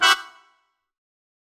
GS_MuteHorn-Emin9.wav